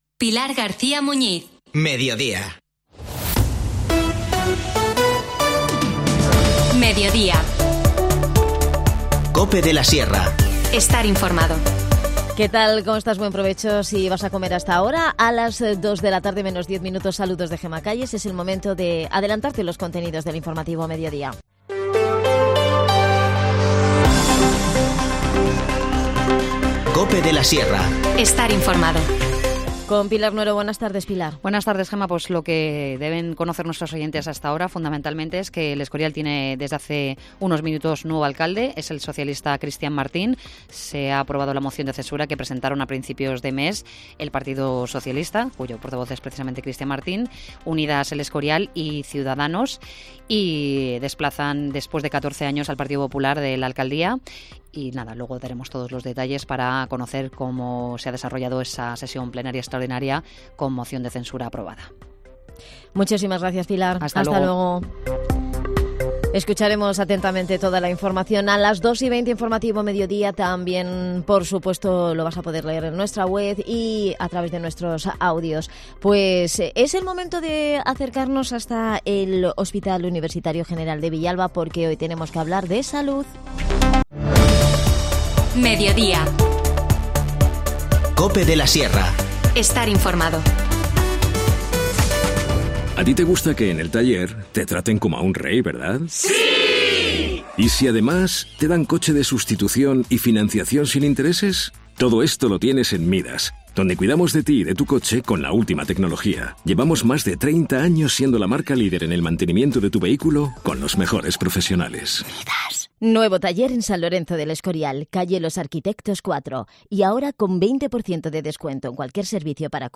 INFORMACIÓN LOCAL
Las desconexiones locales son espacios de 10 minutos de duración que se emiten en COPE, de lunes a viernes.